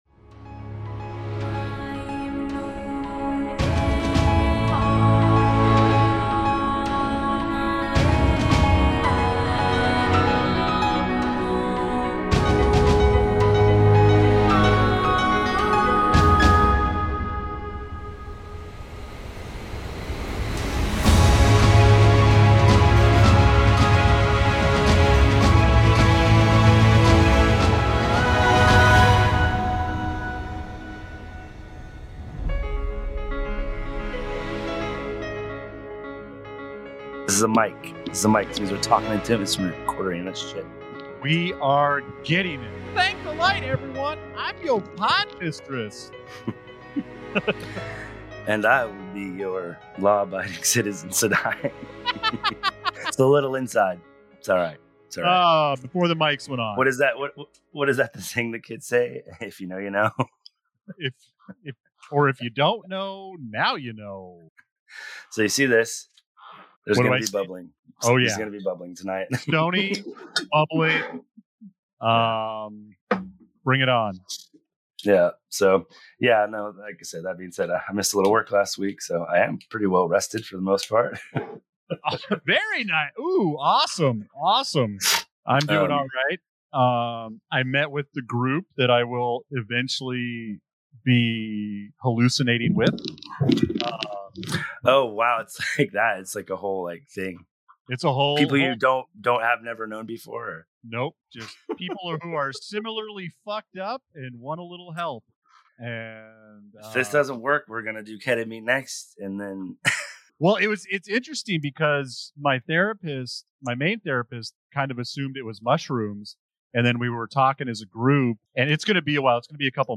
Tune in for a lively discussion filled with theories, laughter, and a deep appreciation for Robert Jordan's epic saga.